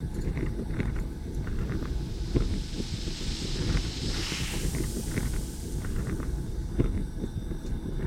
chemplantOperate.ogg